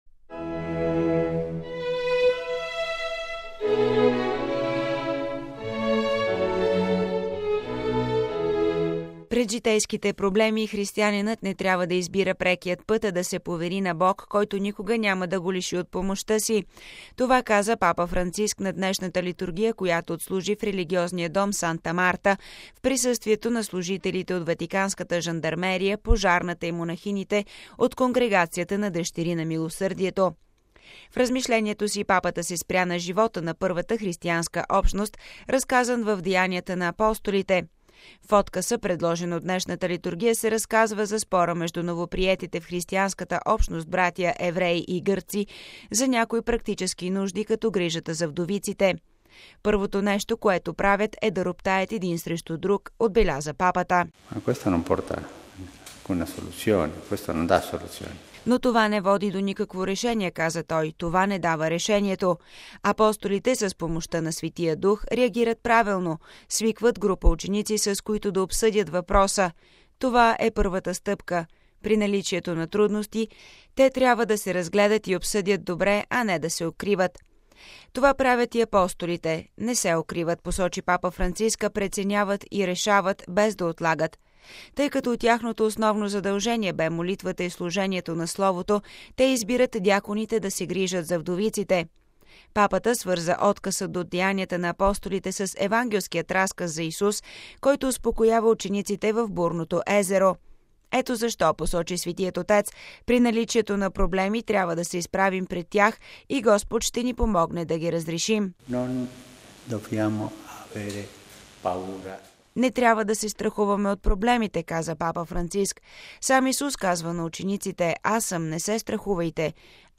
Това каза Папа Франциск на днешната литургия, която отслужи в религиозния дом „Санта Марта” в присъствието на служителите от Ватиканската жандармерия, пожарната и монахините от Конгрегацията на „Дъщери на милосърдието”.